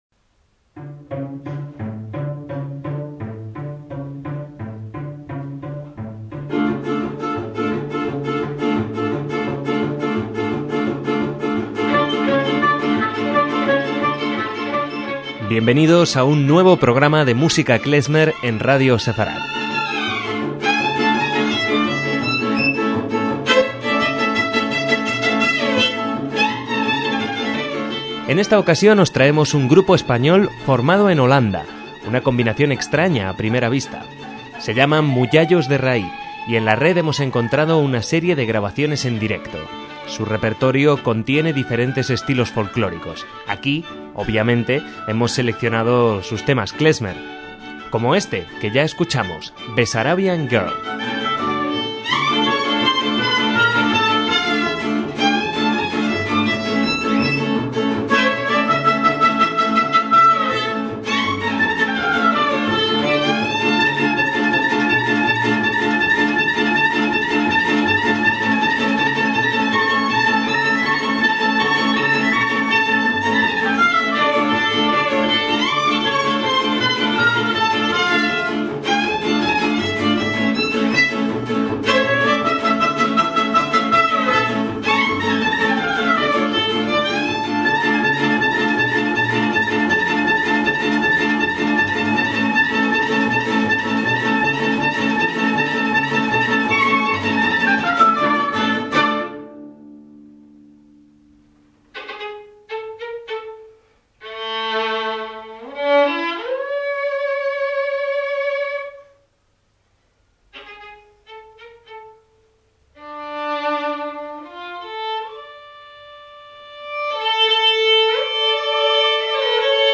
MÚSICA KLEZMER
violín
al oboe, flautas y xaphoon
al contrabajo
en guitarra